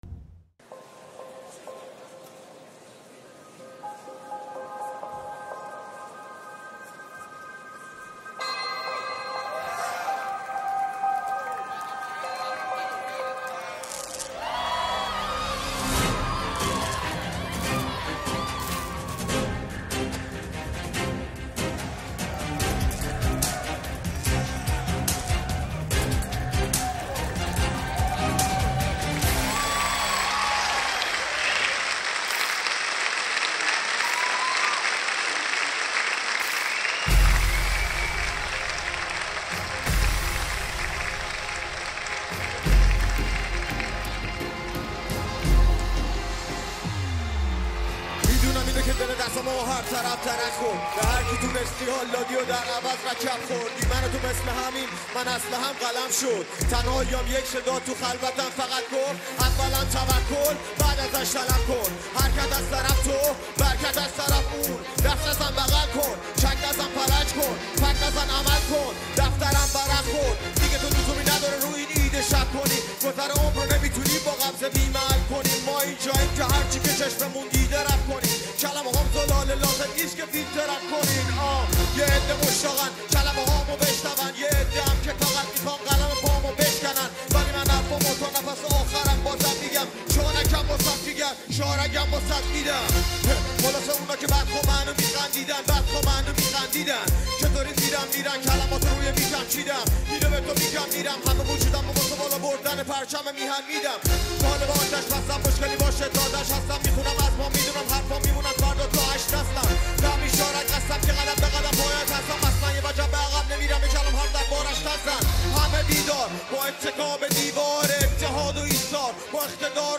Live Concert